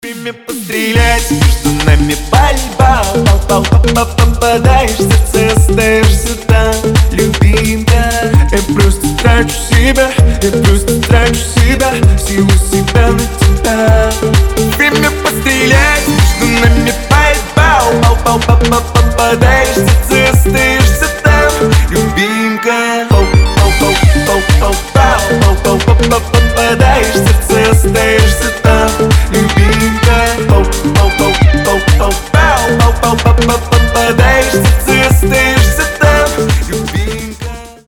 веселая песня.
Танцевальные рингтоны